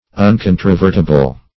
Uncontrovertible \Un*con`tro*ver"ti*ble\, a.
uncontrovertible.mp3